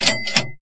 equip.mp3